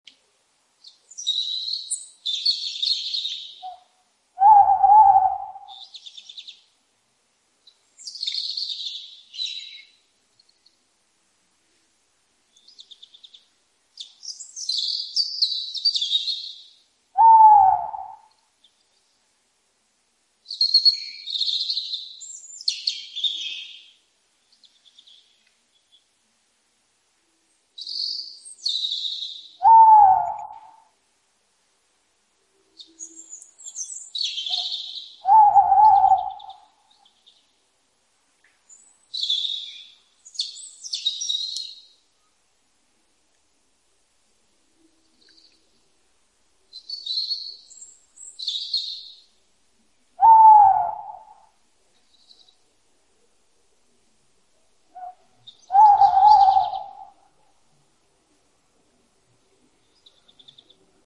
Tawny Owl And Robin Early Night Bouton sonore